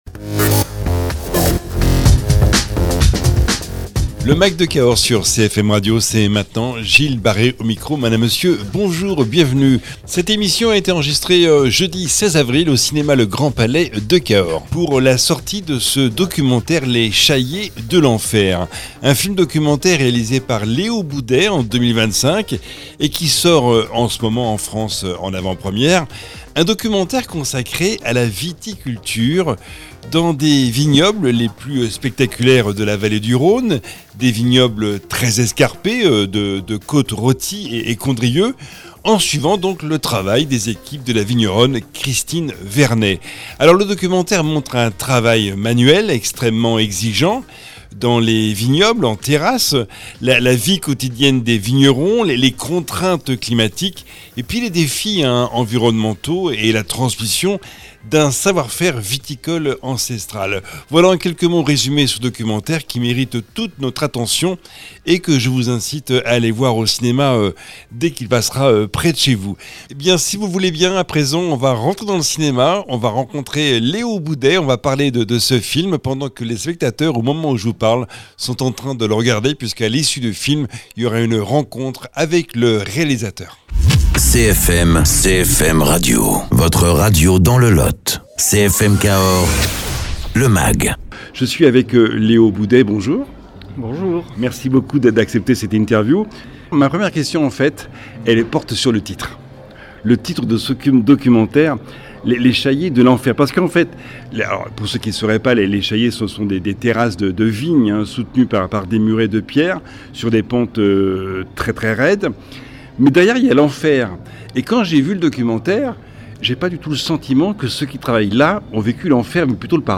Rencontre autour du film Les Chaillées de l’enfer